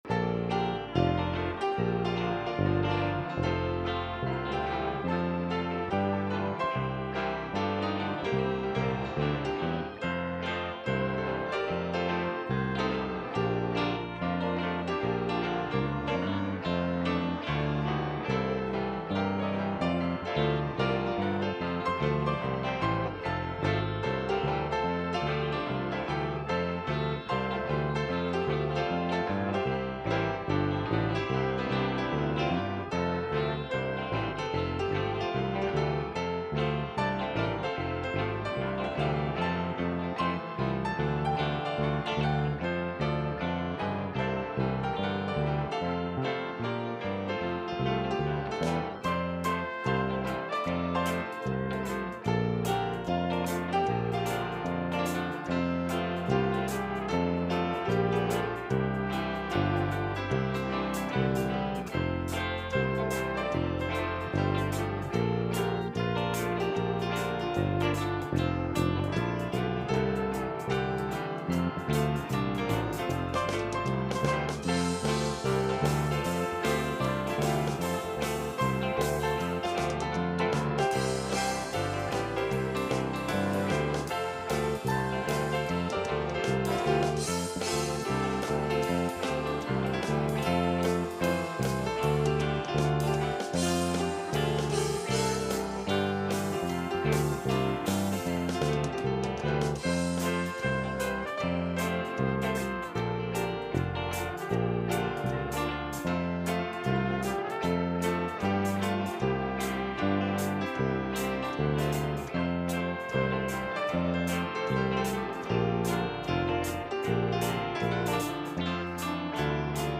The Mosaic Tabernacle- Teaching Series